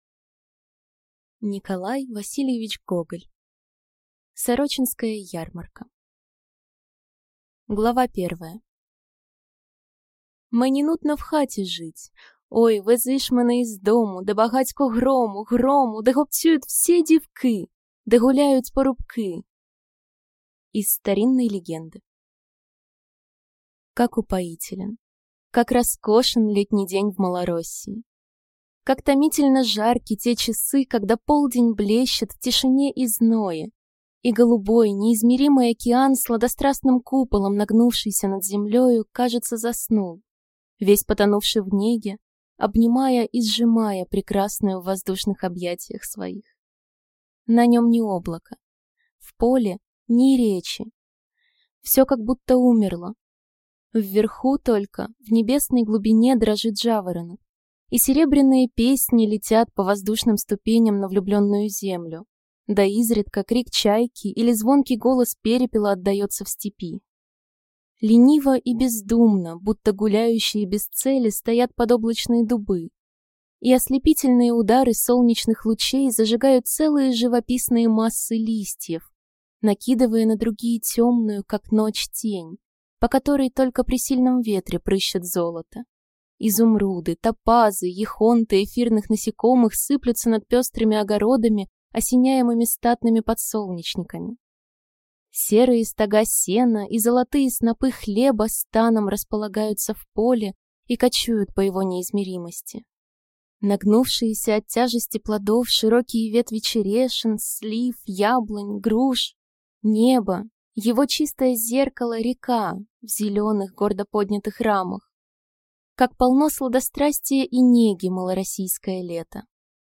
Аудиокнига Сорочинская ярмарка | Библиотека аудиокниг